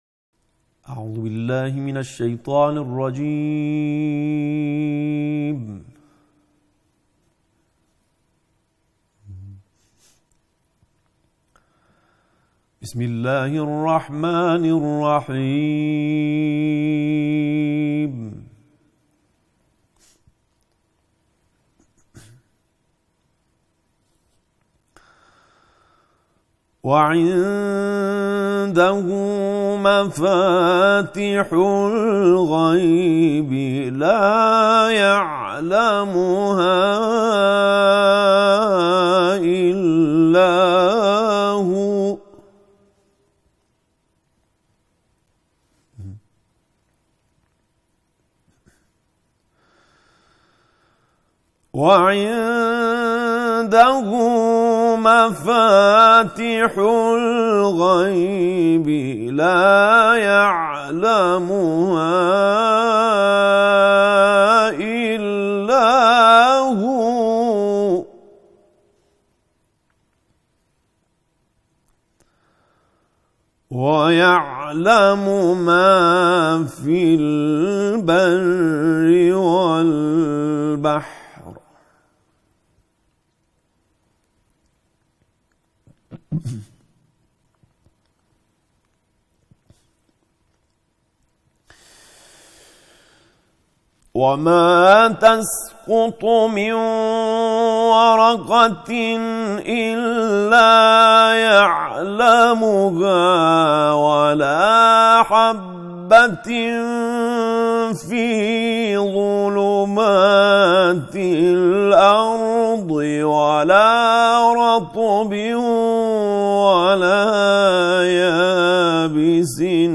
هفدهمین محفل با تلاوت